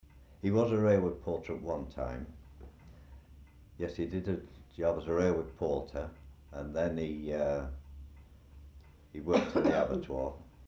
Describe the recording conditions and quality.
Audio B is the same audio laced up correctly with the oxide side facing the playback head. no-longer-muffled-correct-side.mp3